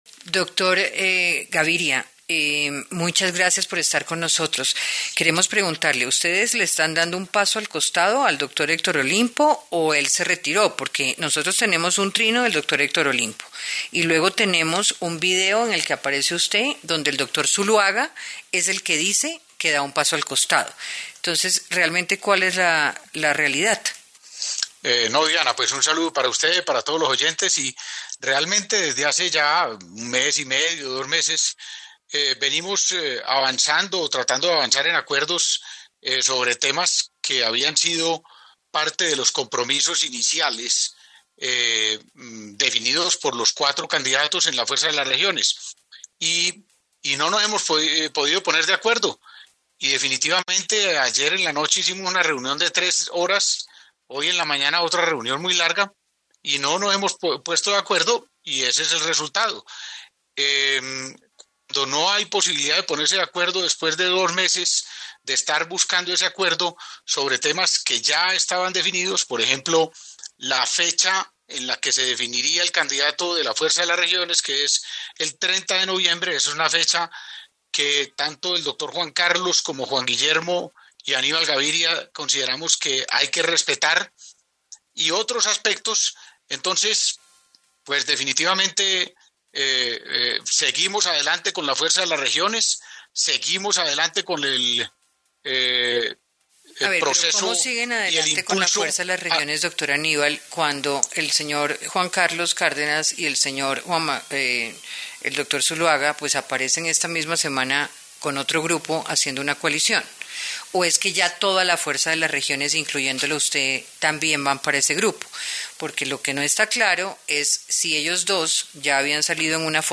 El exgobernador de Antioquia y precandidato presidencial, Aníbal Gaviria, dio la primicia durante el Noticiero del Medio día de Caracol Radio